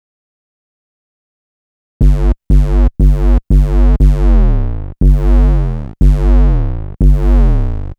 Session 08 - Bass 03.wav